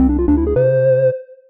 Add sound effects!
win.ogg